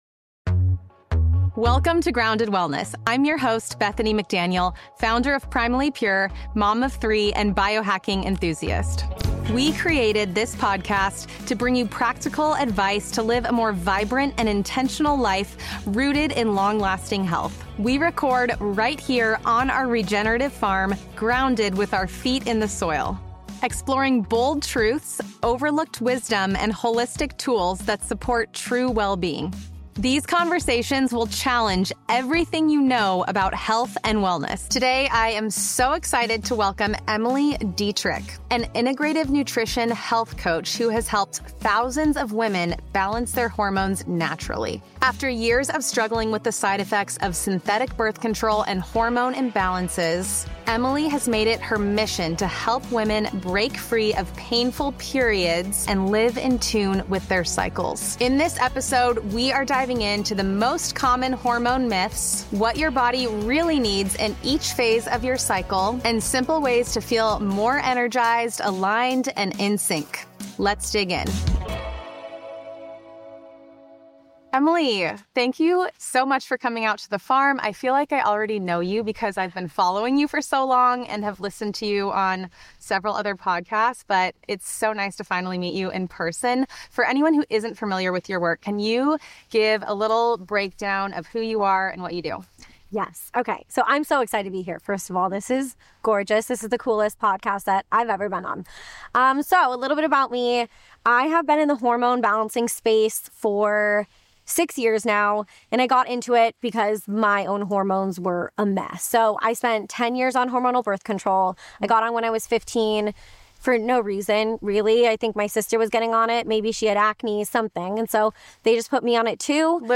It’s a hopeful conversation filled with practical tips and empowering science that every woman deserves to know.